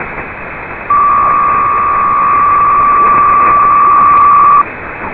psk63